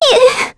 Mirianne-Vox_Damage_02.wav